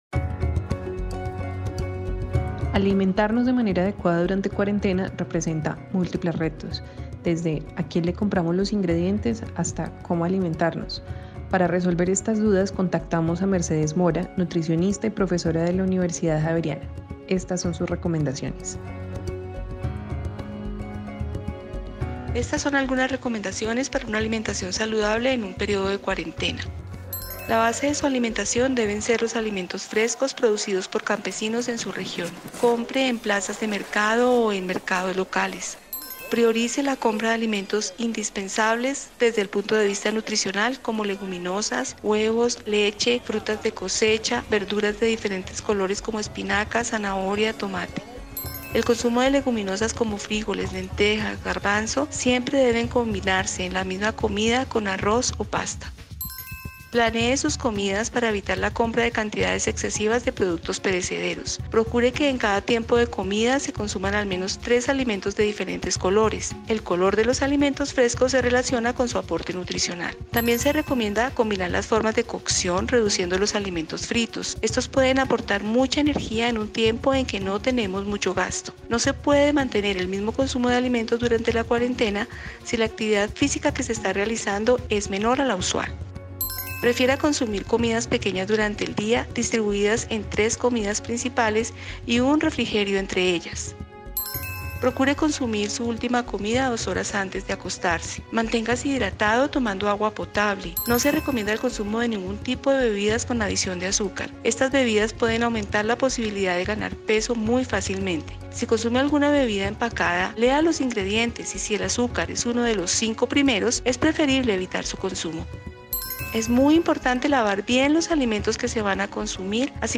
Escucha una serie de audios sobre el derecho a la alimentación, bajo la voz de diferentes expertas.